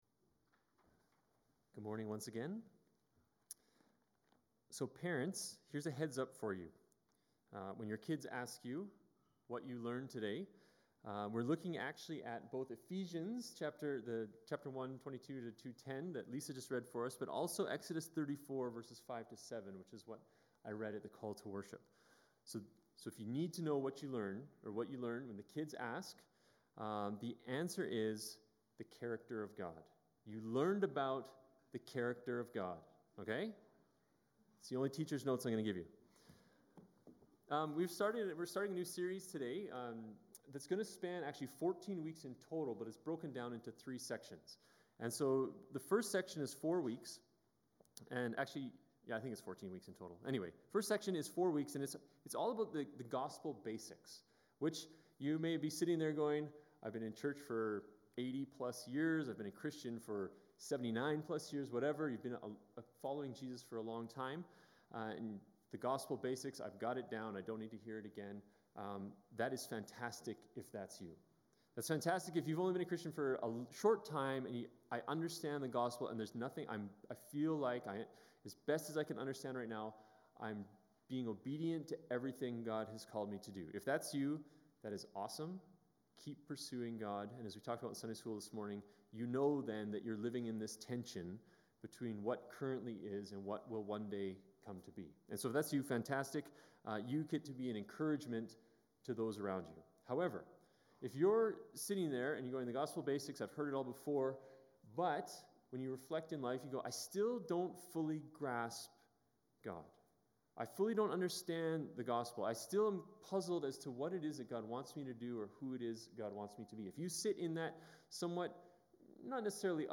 Archived Sermons | Crescent Heights Baptist Church